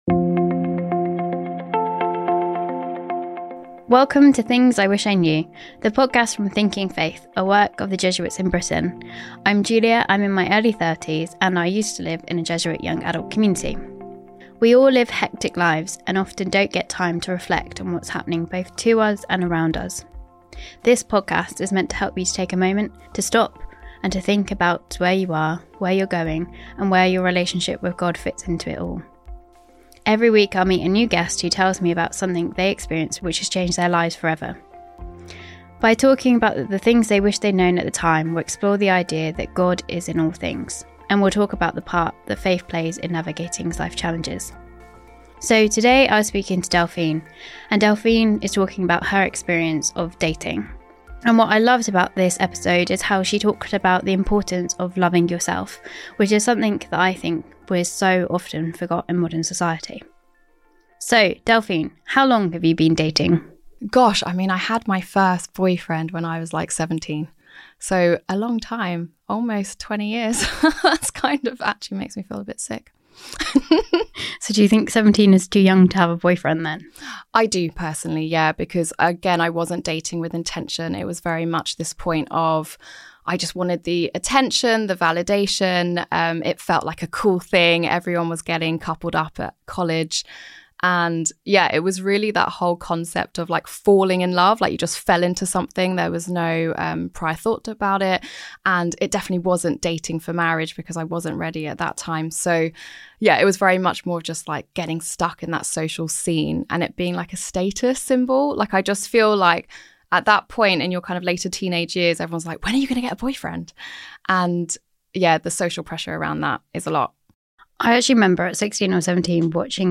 They explore emotional vs. physical attraction, dating with intention, and building confidence in a digital world. A heartfelt conversation on self-love